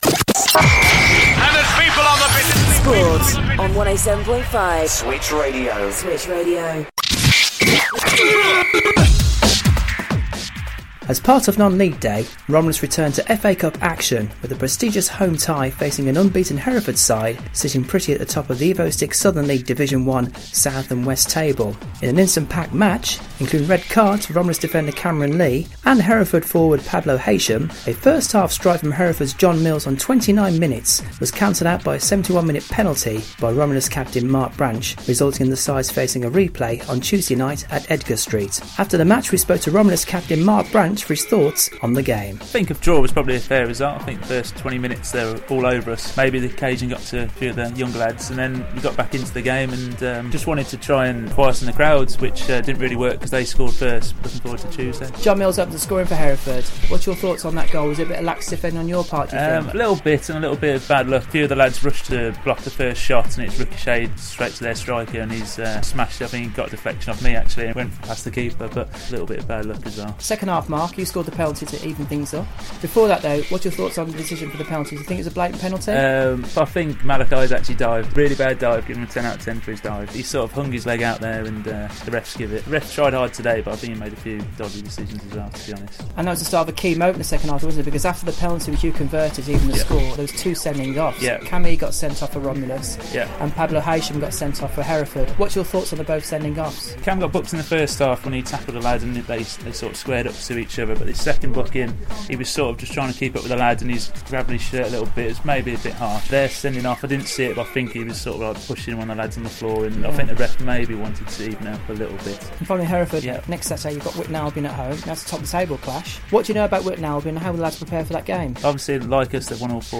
post match reaction